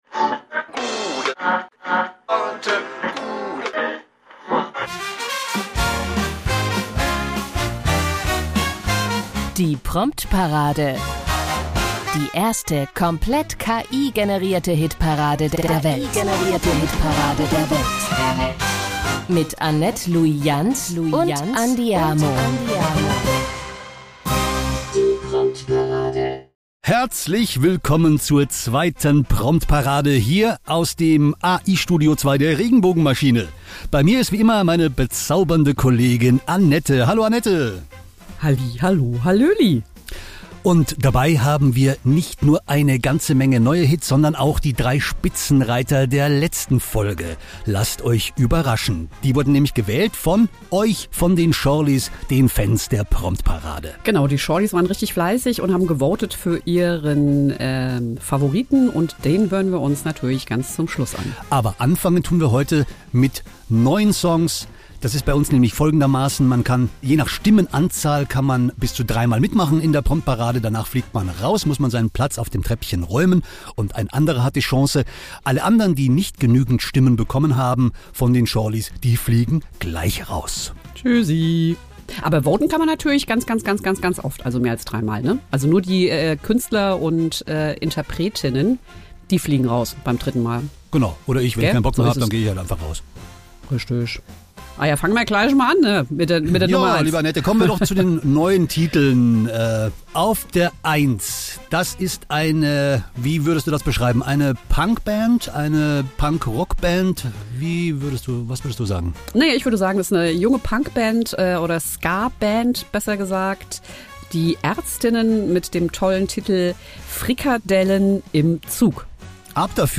Hier wurde weder etwas eingespielt, noch etwas eingesungen . ALLE Songs wurden gänzlich von einer künstlichen Intelligenz umgesetzt.